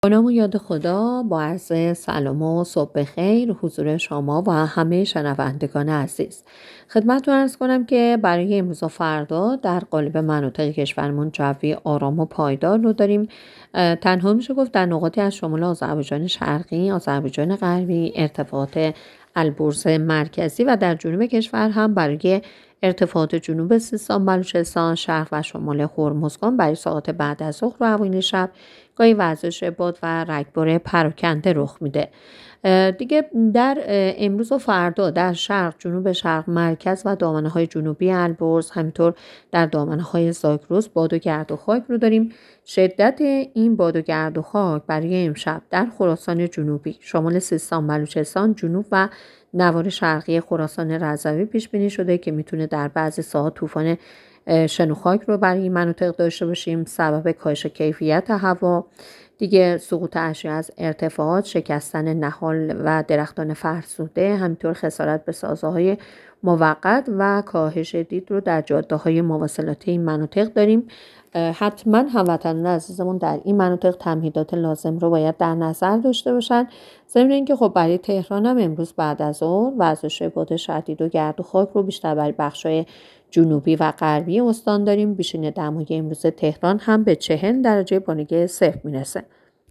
گزارش رادیو اینترنتی پایگاه‌ خبری از آخرین وضعیت آب‌وهوای ۲۷ تیر؛